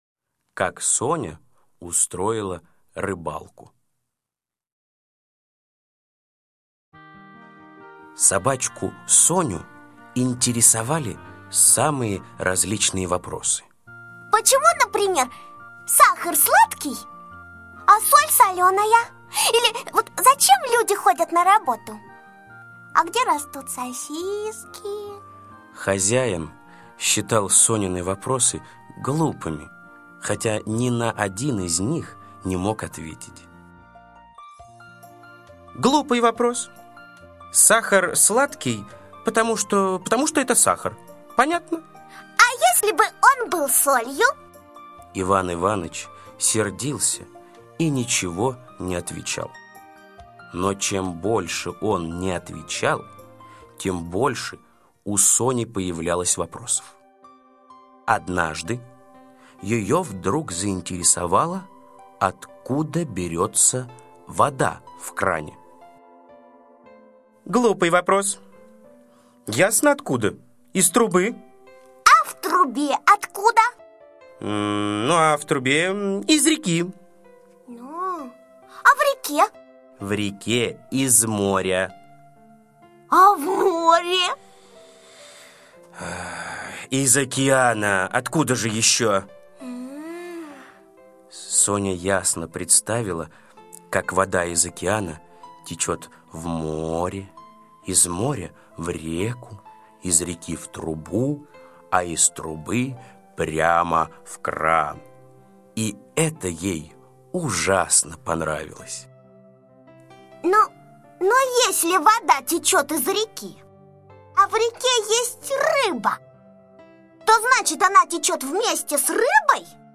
Слушайте Как Соня устроила рыбалку - аудиосказка Усачева А.А. Хозяин сказал, что вода в кране появляется из моря.